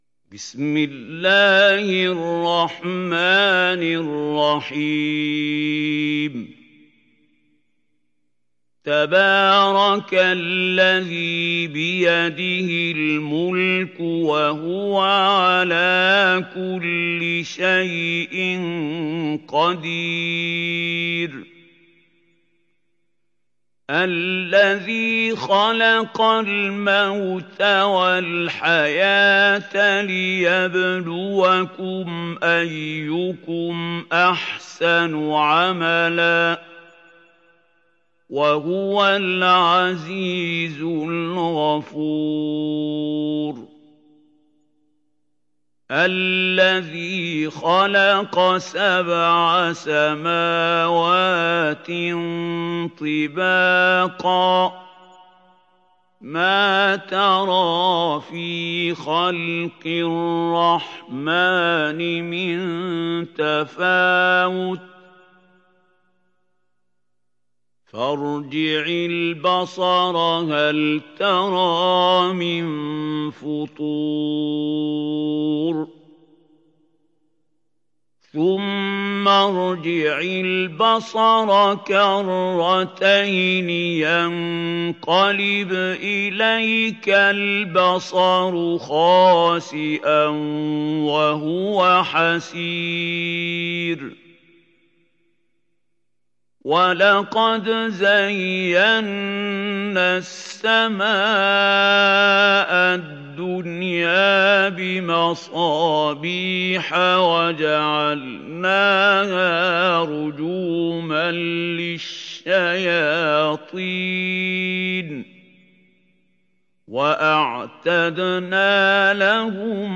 دانلود سوره الملك mp3 محمود خليل الحصري روایت حفص از عاصم, قرآن را دانلود کنید و گوش کن mp3 ، لینک مستقیم کامل
دانلود سوره الملك محمود خليل الحصري